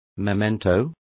Complete with pronunciation of the translation of mementos.